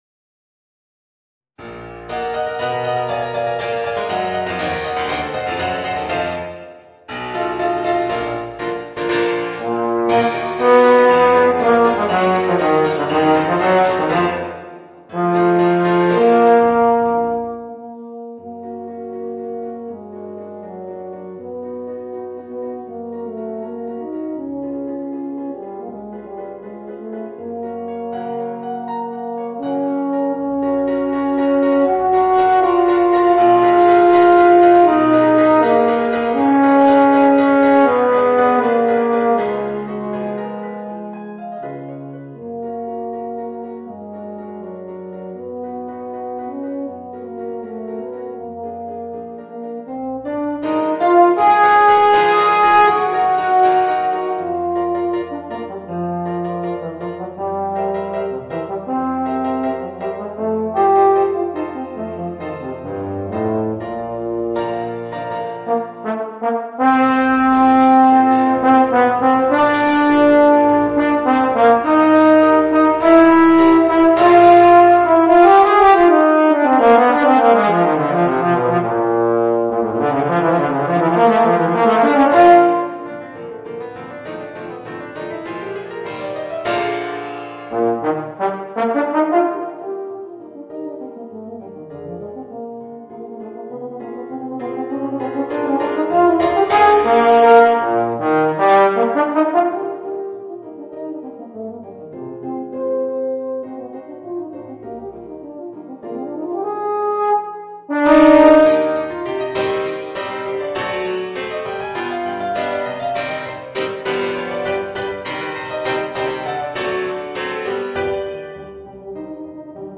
Voicing: Euphonium and Piano